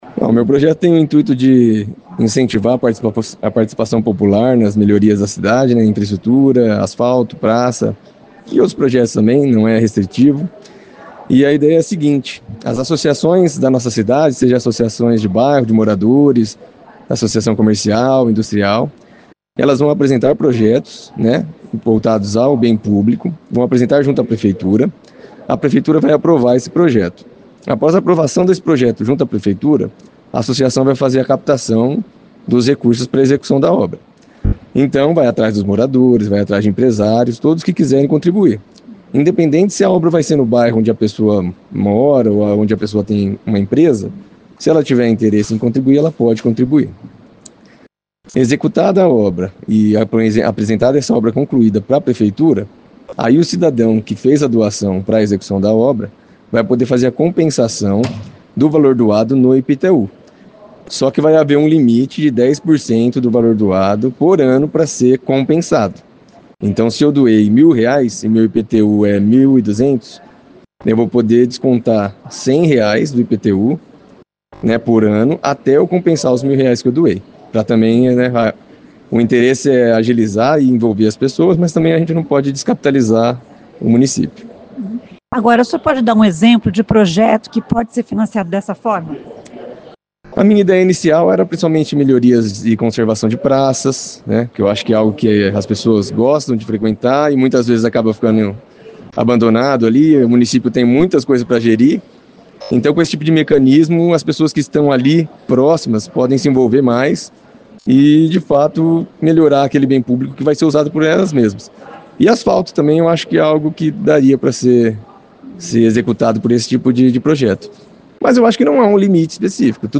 Ouça a explicação de vereador: